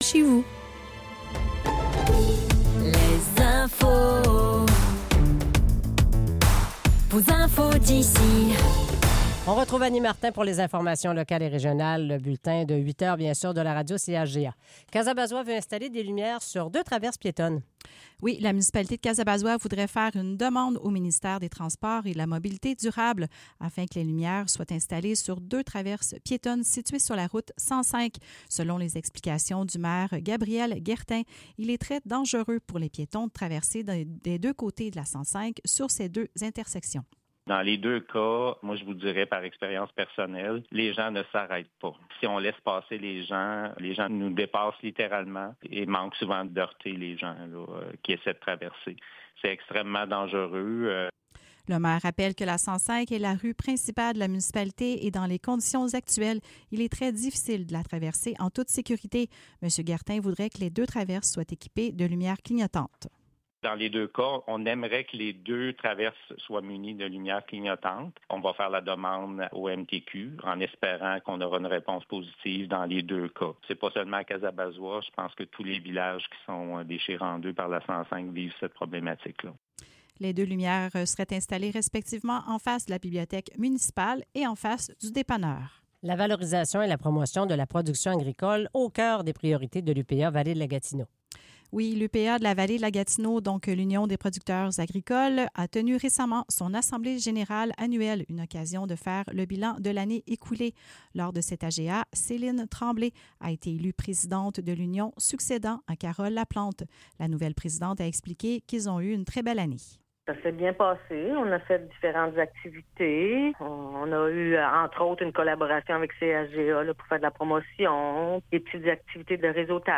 Nouvelles locales - 30 mars 2026 - 8 h